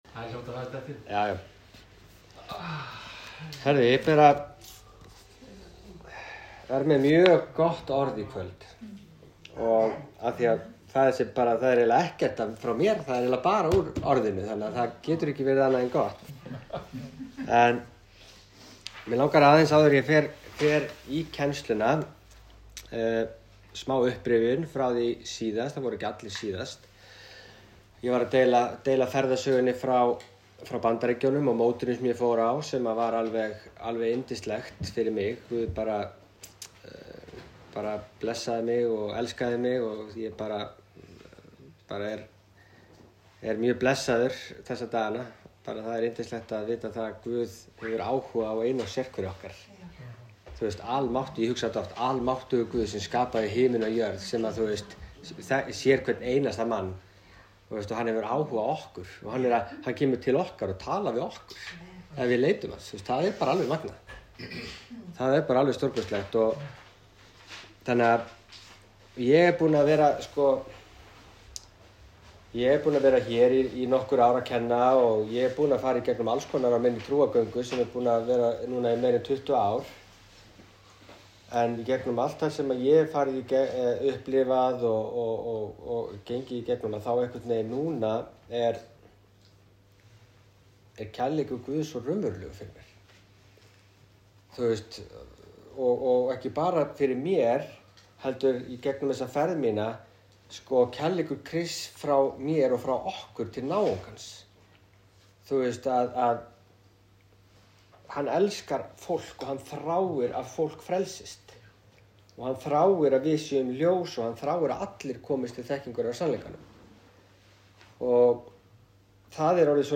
Ég mæli með kennslunni sem ég var að setja inn á síðuna en þar er hægt að hlusta á upptöku þar sem ég fór ítarlega í þetta efni í heimahópnum okkar.